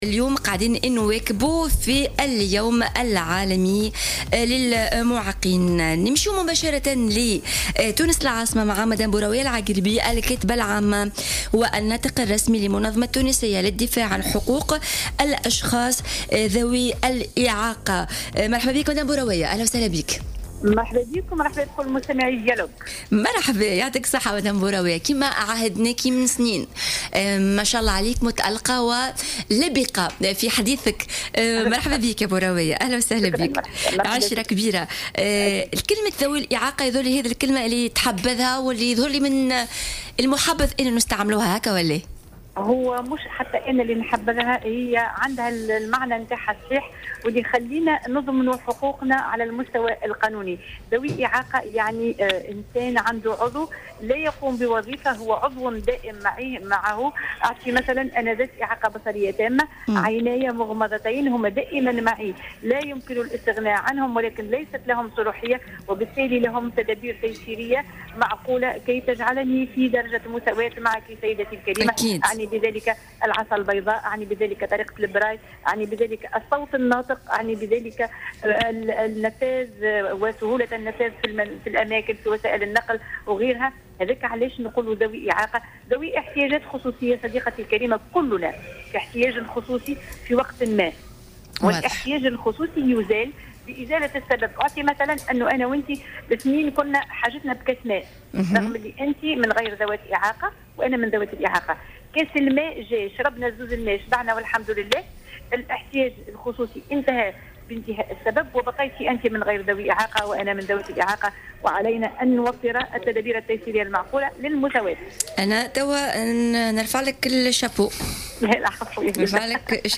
خلال مداخلتها في برنامج "dialogue" على موجات الجوهرة اف ام